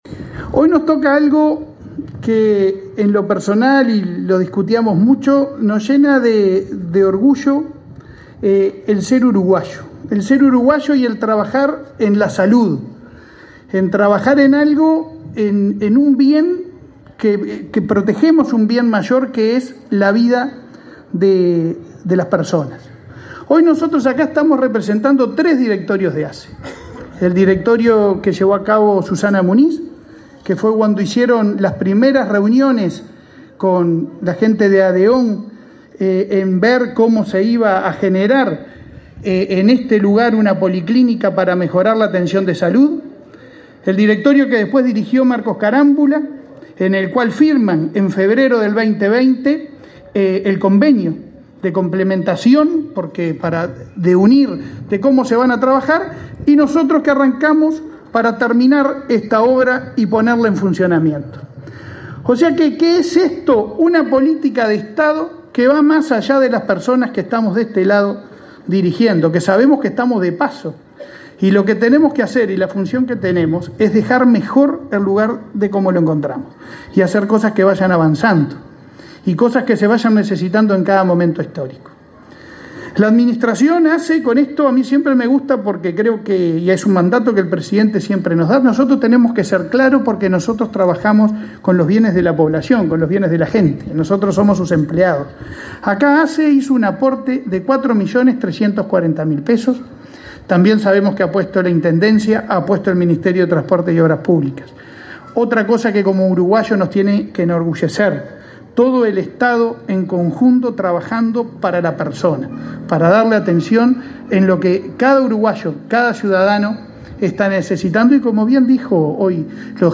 Palabras del presidente de ASSE y el subsecretario de Transporte
Palabras del presidente de ASSE y el subsecretario de Transporte 27/09/2022 Compartir Facebook X Copiar enlace WhatsApp LinkedIn El presidente de ASSE, Leonardo Cipriani, y el subsecretario de Transporte, Juan José Olaizola, participaron en la inauguración de una policlínica del prestador público en la ciudad de Fray Bentos, departamento de Río Negro.